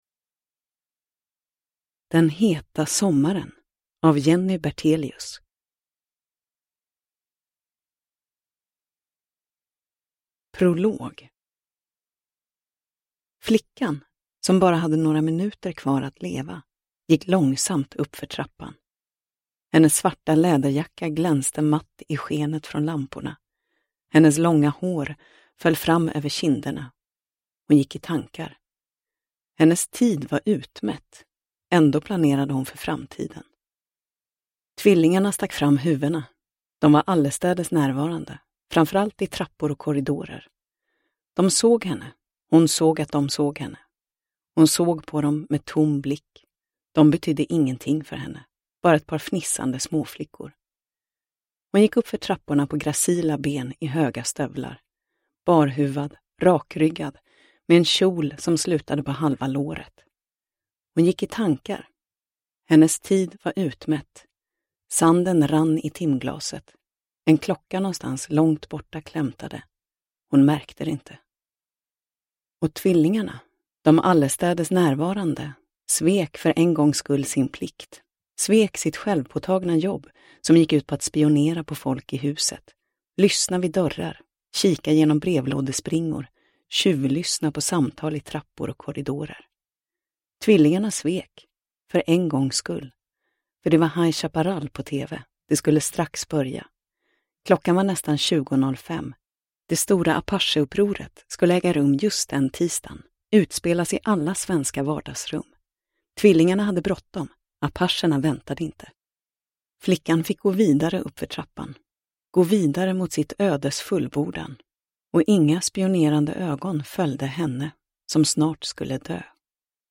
Den heta sommaren – Ljudbok – Laddas ner